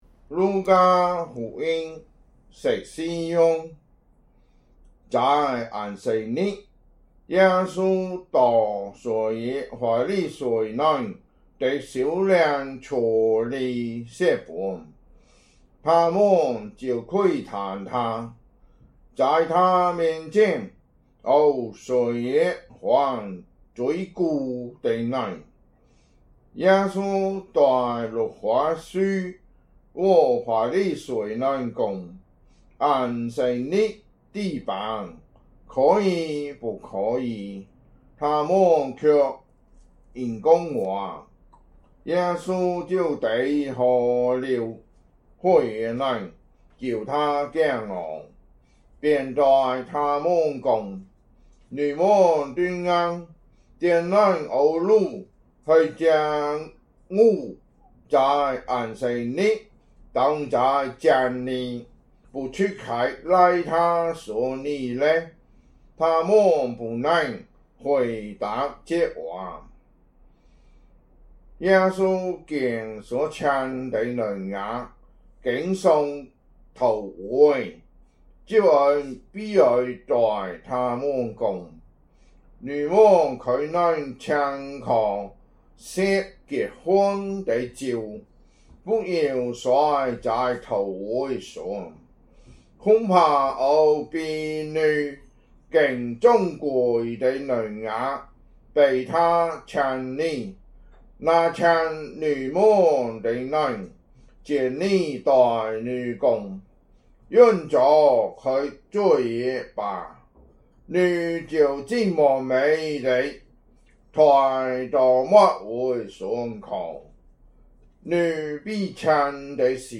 福州話有聲聖經 路加福音 14章